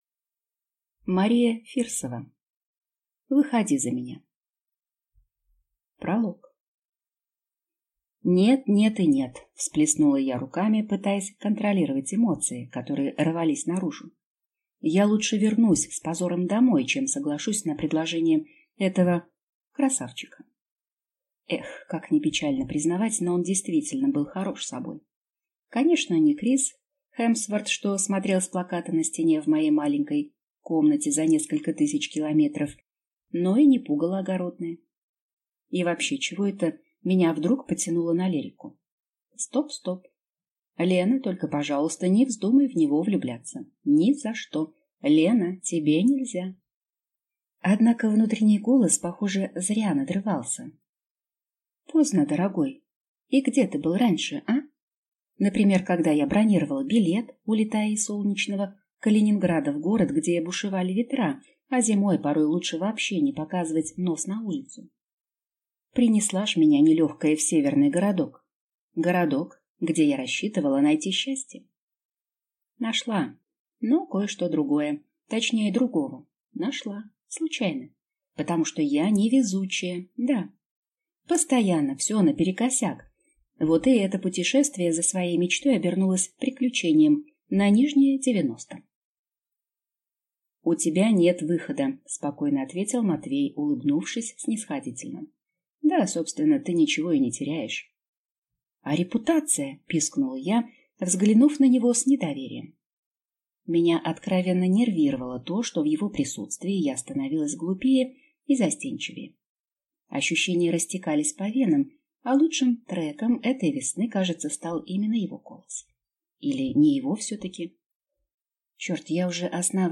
Аудиокнига Выходи за меня | Библиотека аудиокниг